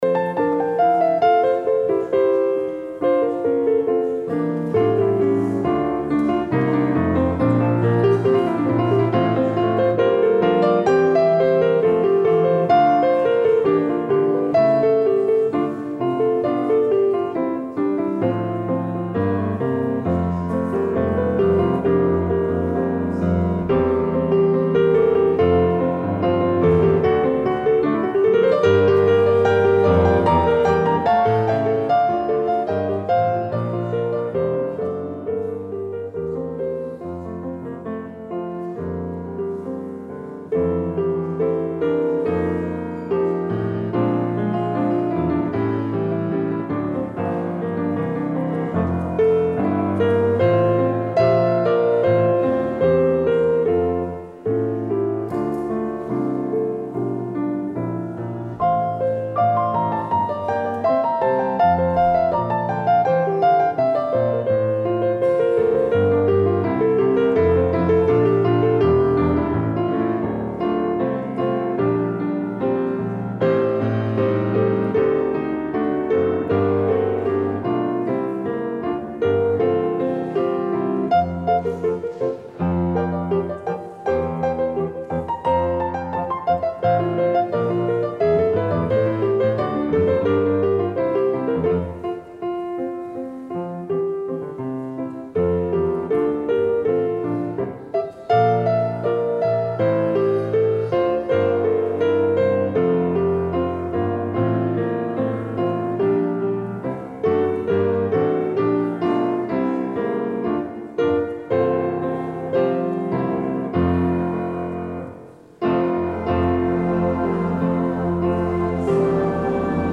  Luister deze kerkdienst hier terug
Het openingslied is: Gezang 139.
Het slotlied is: Opwekking 527.